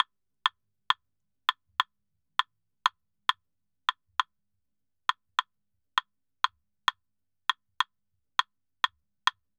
Claves_Salsa 100_2.wav